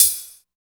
84 HAT 2  -R.wav